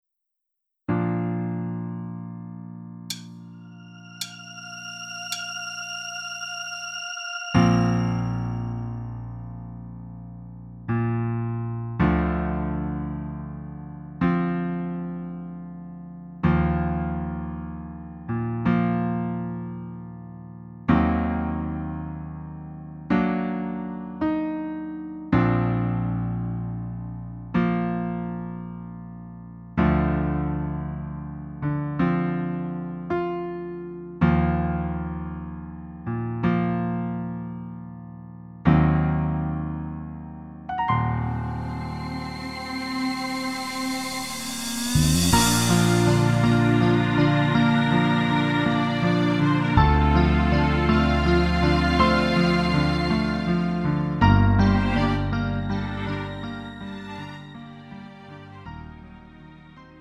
음정 -1키 4:05
장르 가요 구분 Lite MR
Lite MR은 저렴한 가격에 간단한 연습이나 취미용으로 활용할 수 있는 가벼운 반주입니다.